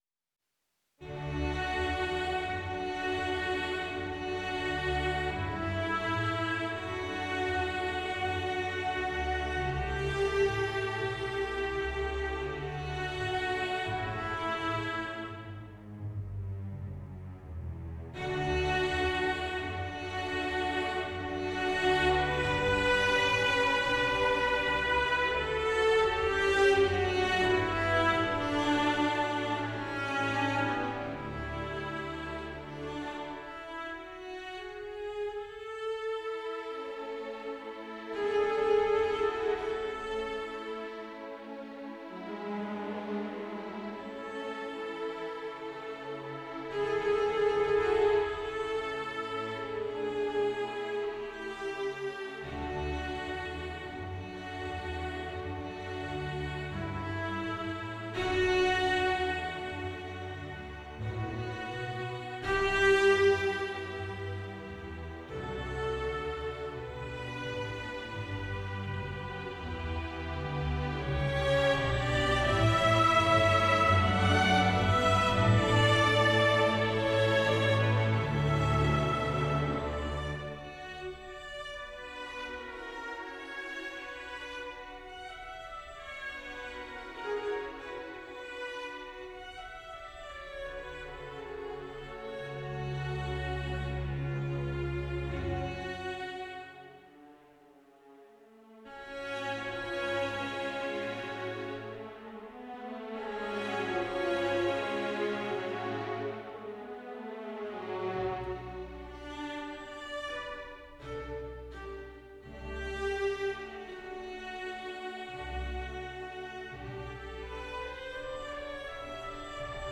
Opus 002 – Sonate (3) in fa minore, la maggiore e do maggiore per pianoforte (1794-primavera 1795)